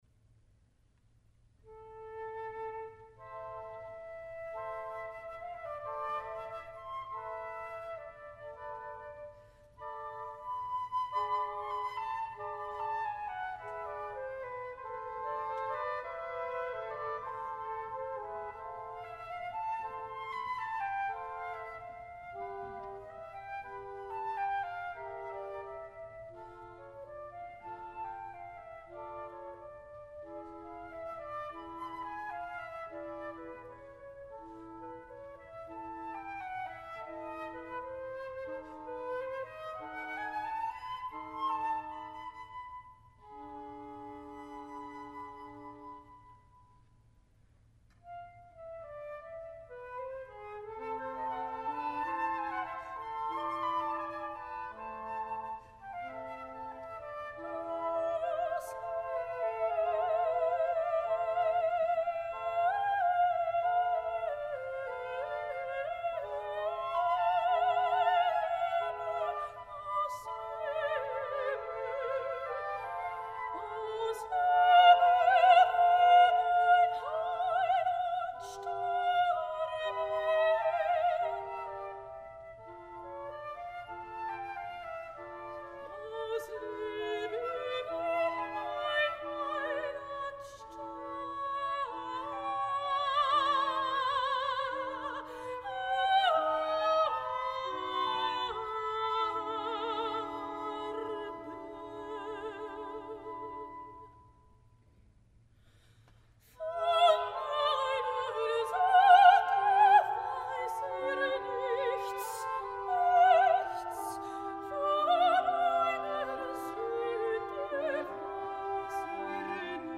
Symphony Hall, Birmingham
soprano